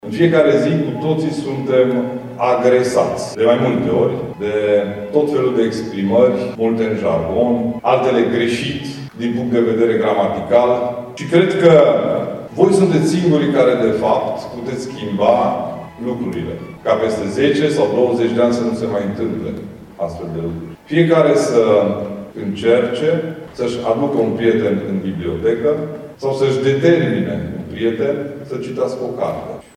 Deschiderea oficială a competiţiei a avut loc aseară, în prezenţa elevilor participanţi, ai profesorilor, ai inspectorilor şcolari şi ai reprezentanţilor autorităţilor locale.
Preşedintele Consiliului Judeţean Mureş, Ciprian Dobre, le-a explicat elevilor cât de importanţi sunt pentru păstrarea unei limbi române cât mai corecte.